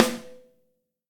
snare.ogg